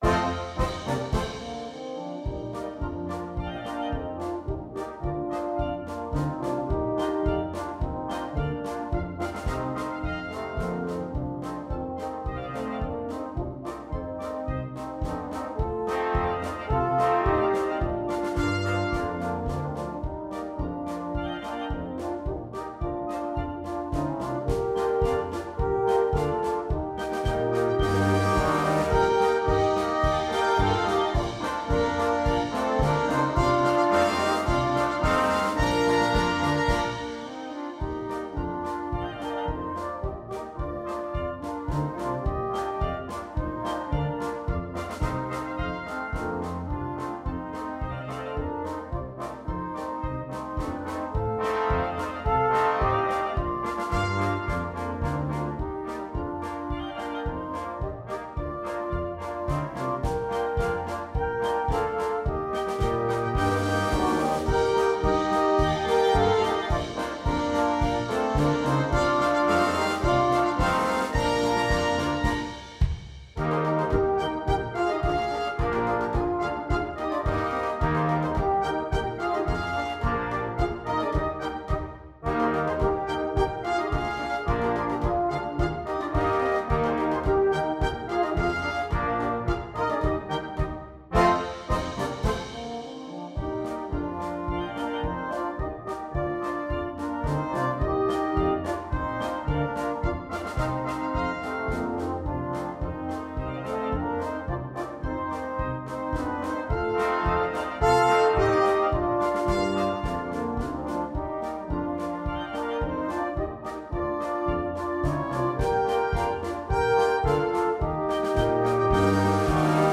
2. Blaskapelle
komplette Besetzung
ohne Soloinstrument
Polka